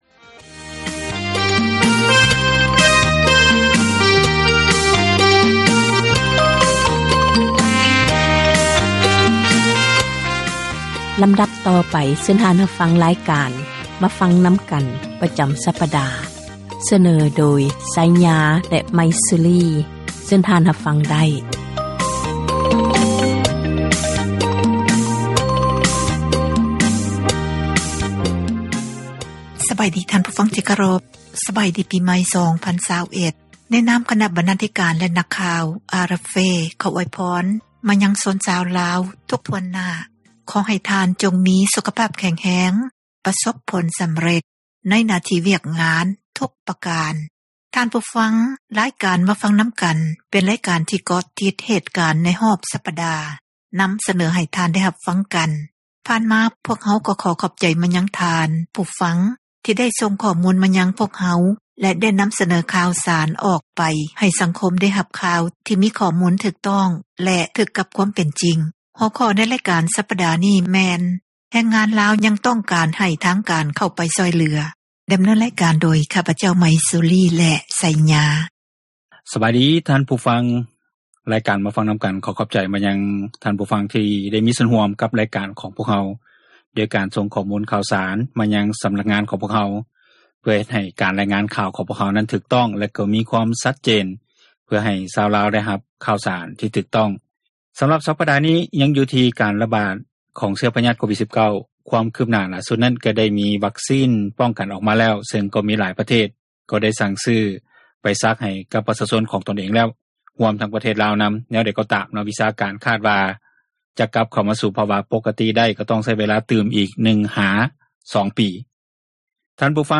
ການສົນທະນາ ໃນບັນຫາ ແລະ ຜົລກະທົບ ຕ່າງໆ ທີ່ເກີດຂຶ້ນ ຢູ່ ປະເທດລາວ